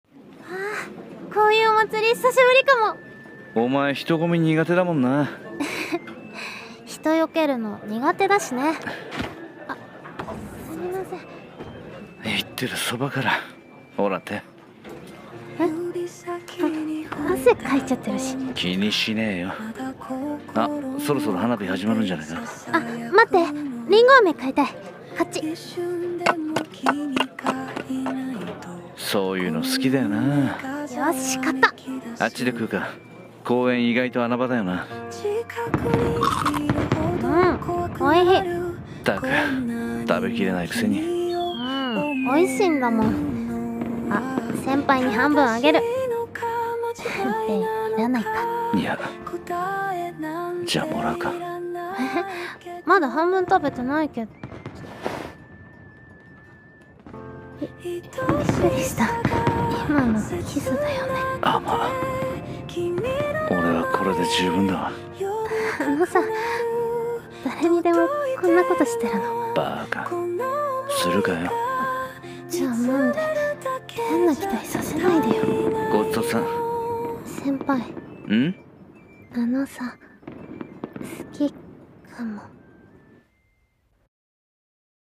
それはリンゴ飴の味 【2人声劇】